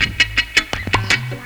RIFFGTRLP1-R.wav